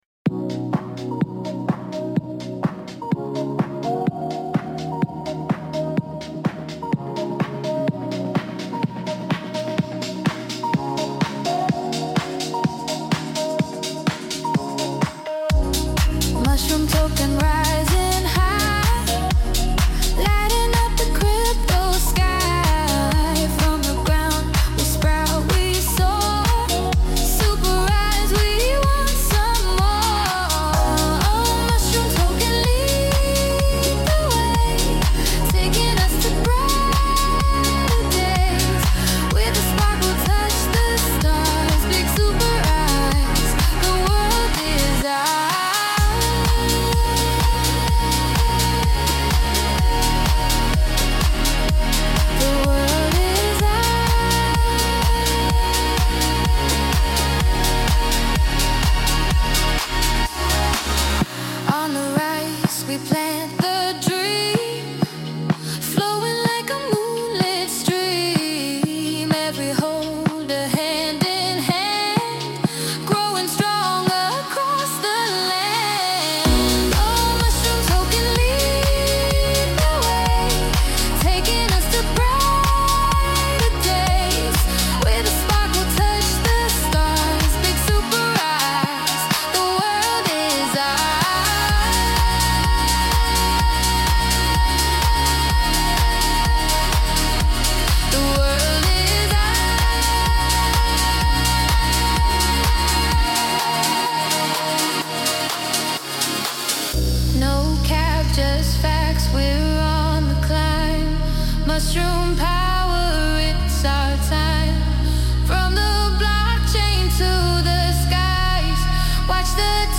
mushroom-ambience.mp3